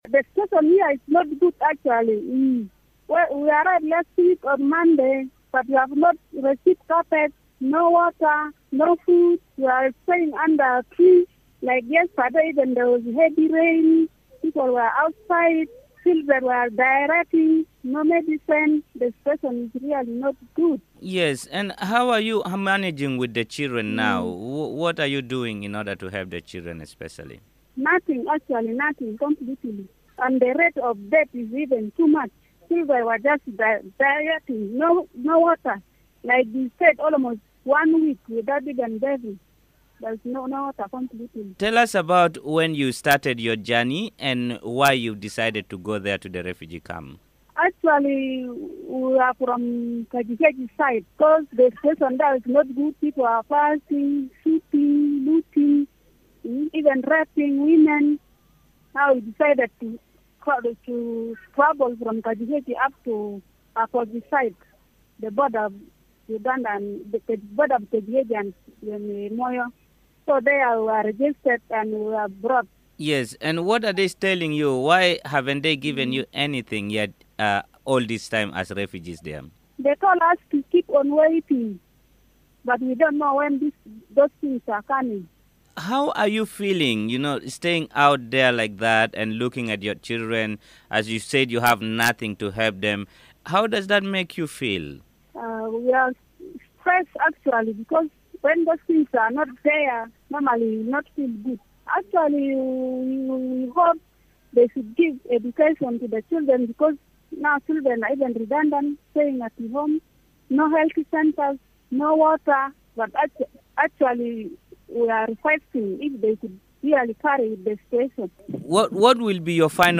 Earlier, I spoke to two women who spoke said the lack of water, food, medical facilities and education children in the transit centers has created a vacuum, and that had turned some young people to hopelessness